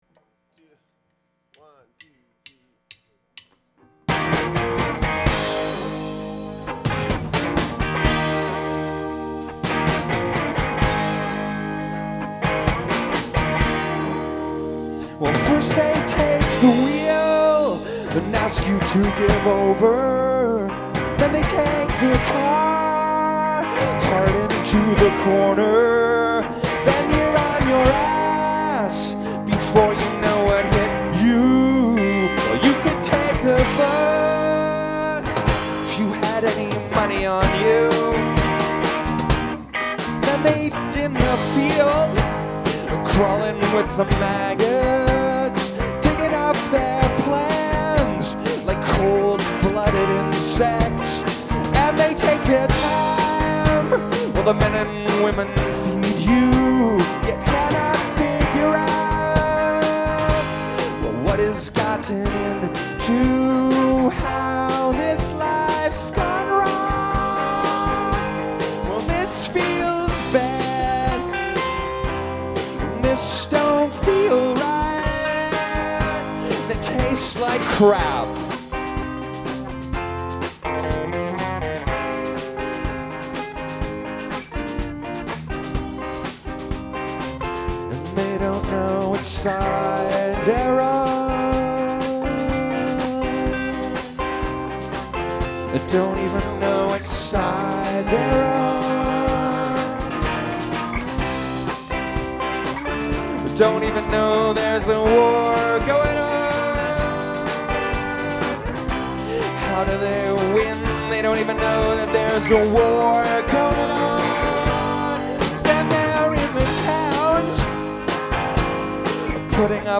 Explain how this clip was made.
The Horseshoe Tavern Toronto Recording: Soundboard > Sony SBM-1 > Sony D8